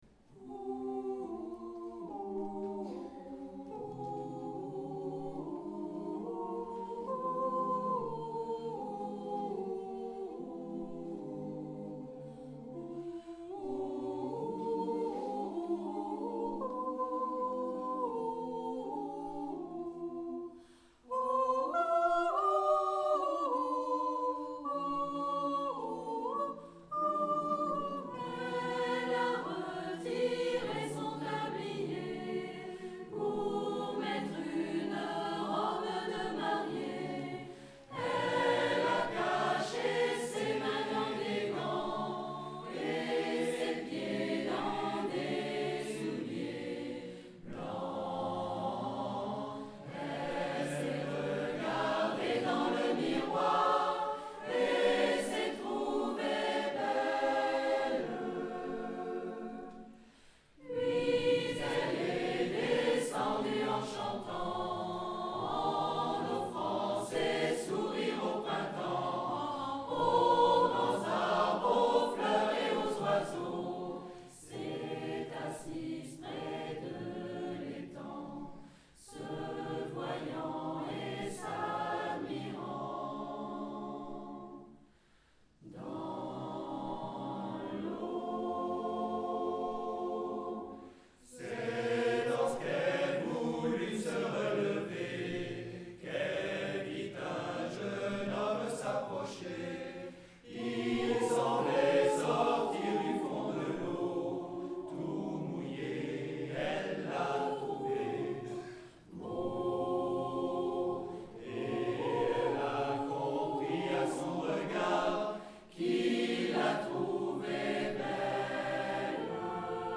Repris dans une polyphonie à 8 voix